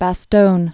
(bă-stōn, bă-stônyə)